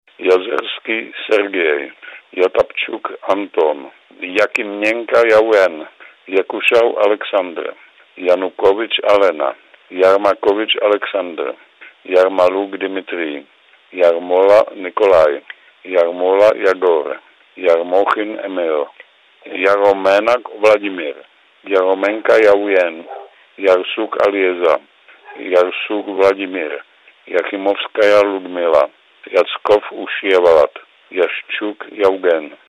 Havel čita imena uhapšenih aktivista